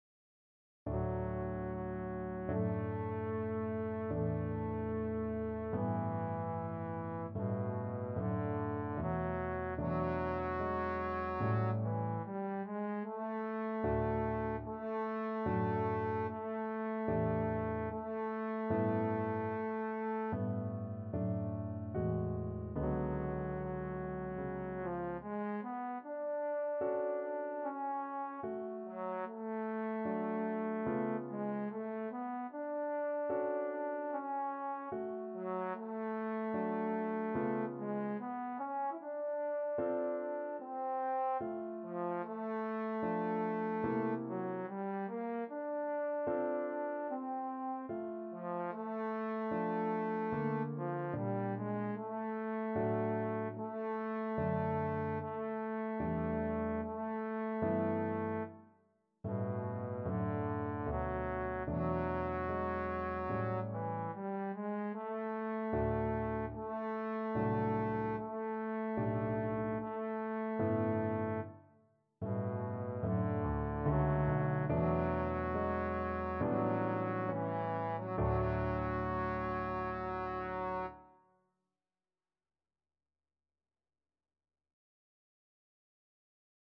Classical (View more Classical Trombone Music)